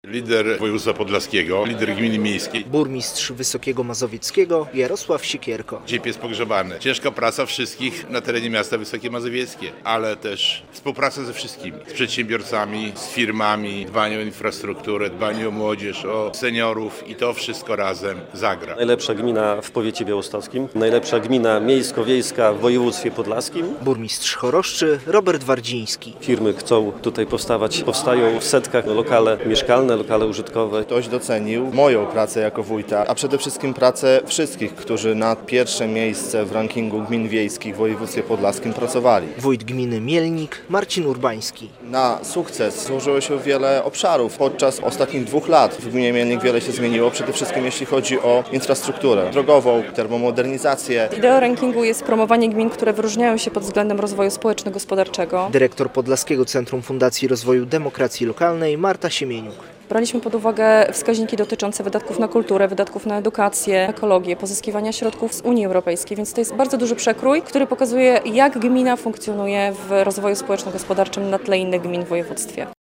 Znamy już liderów Rankingu Gmin Województwa Podlaskiego - relacja